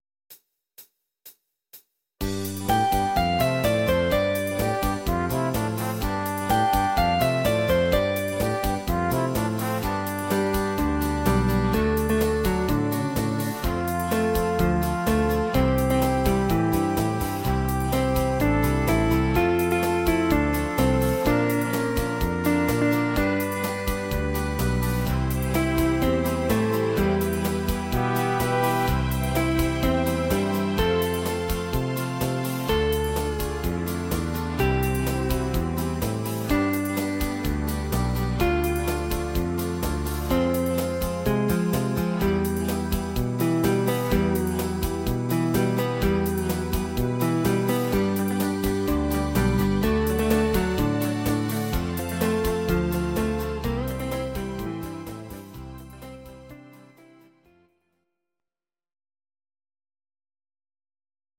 These are MP3 versions of our MIDI file catalogue.
Please note: no vocals and no karaoke included.
Gitarre